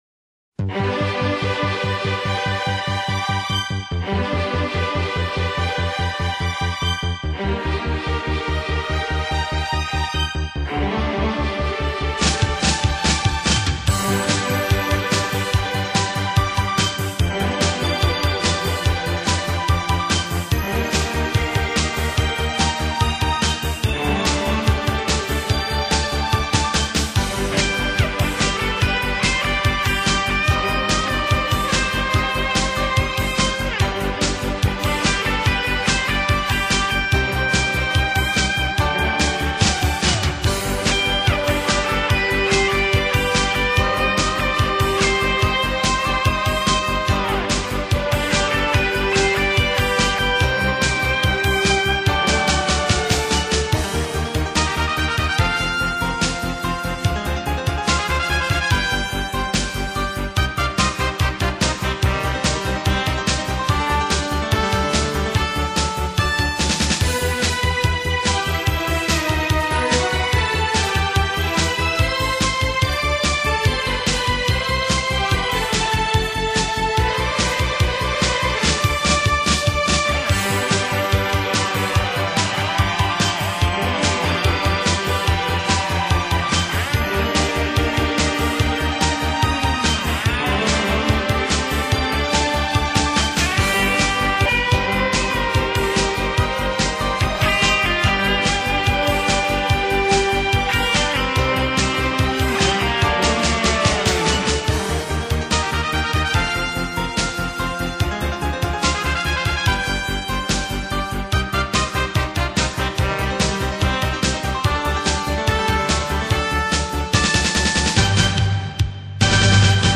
(오케스트라 사운드가 거의 사용되지 않았으며 기계적인 사운드에만 의존.)